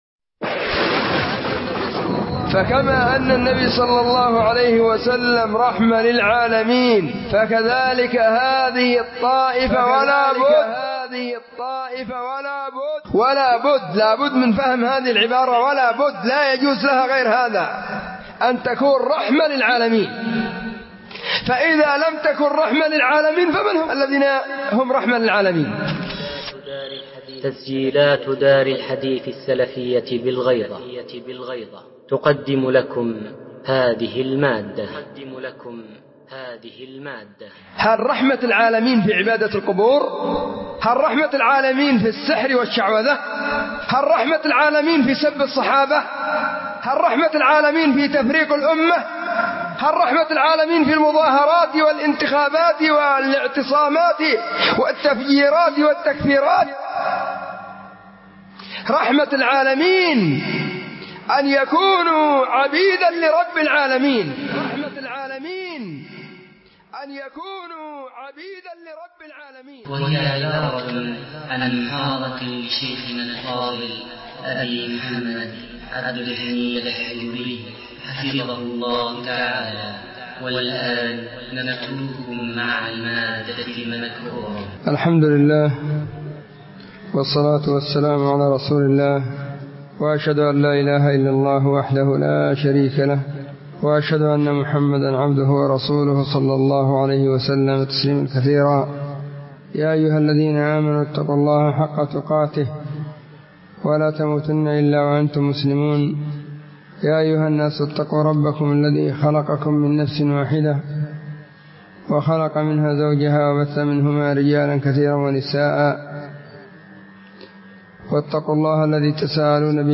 محاضرة ألقيت في مسجد سلمان بالغيضة
📢 مسجد الصحابة – بالغيضة – المهرة، اليمن حرسها الله،
محاضرة-في-مسجد-سلمان-بالغيضة.mp3